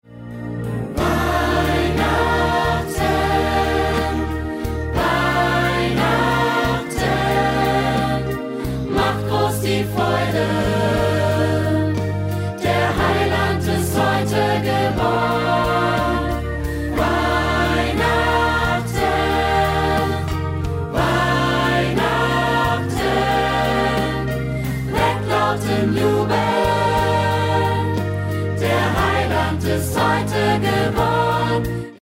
Notation: SATB + Backings
Tonart: Eb
Taktart: 6/8
Tempo: 60 bpm
Noten (Chorsatz)